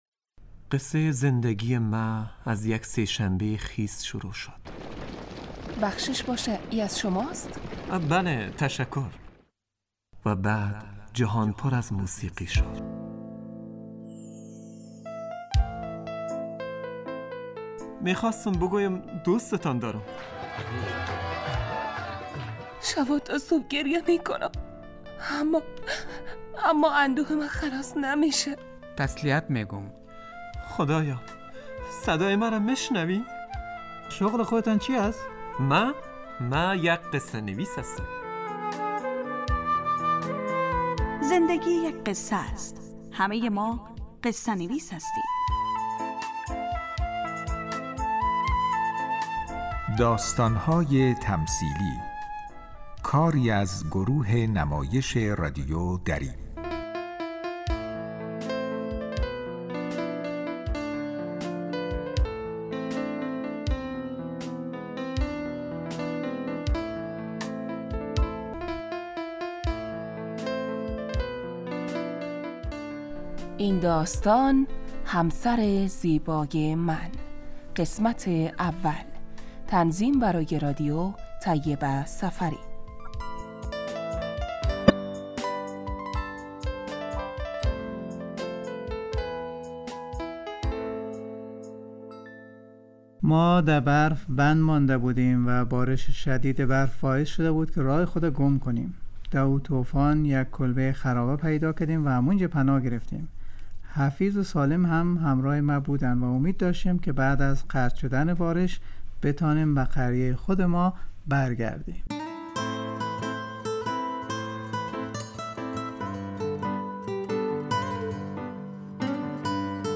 داستانهای تمثیلی نمایش 15 دقیقه ای هستند که روزهای دوشنبه تا پنج شنبه ساعت 03:25عصربه وقت وافغانستان پخش می شود.